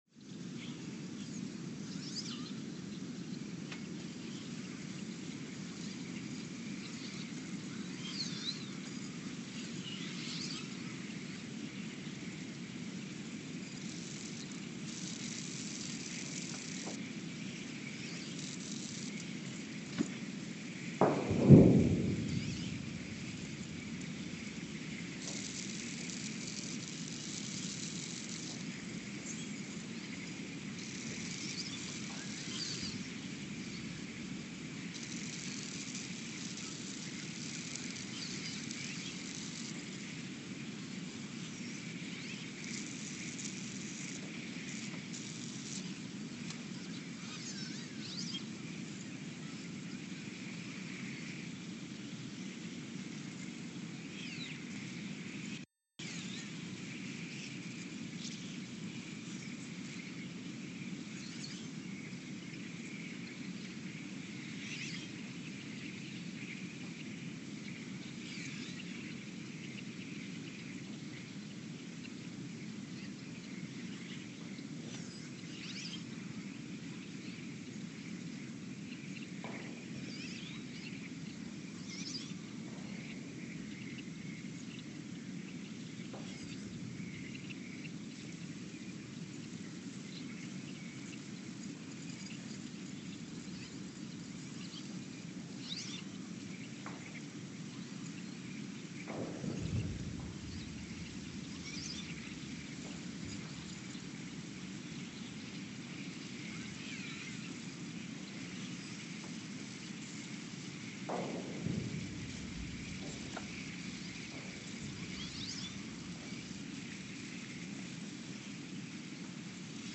Ulaanbaatar, Mongolia (seismic) archived on May 21, 2024
Sensor : STS-1V/VBB
Speedup : ×900 (transposed up about 10 octaves)
Loop duration (audio) : 03:12 (stereo)
Gain correction : 25dB